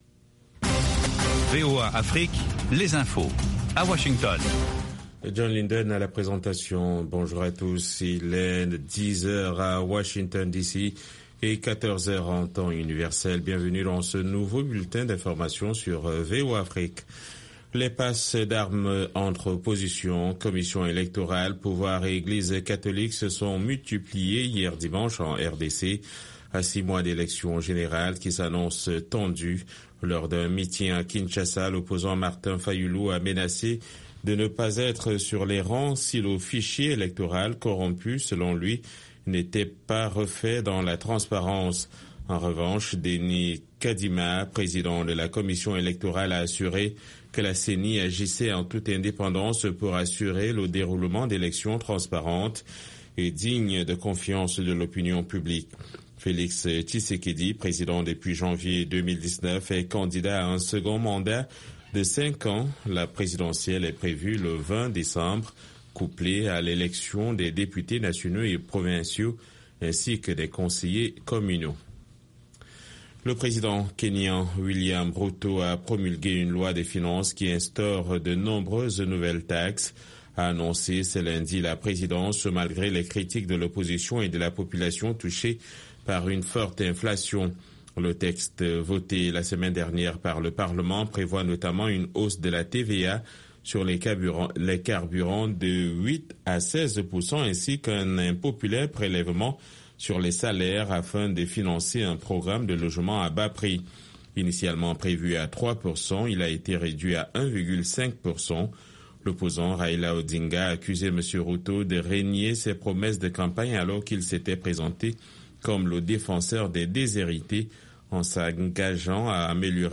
Bulletin d’information de 18 heures